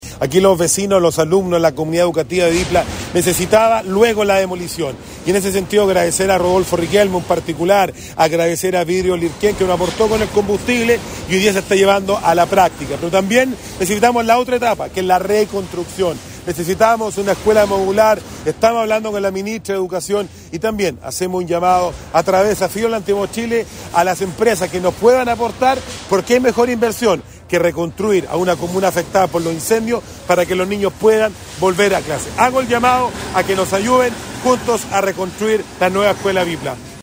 Rodrigo Vera, alcalde de Penco, afirmó que es un momento emotivo para la comunidad educativa y agradeció a quienes colaboraron en este hito.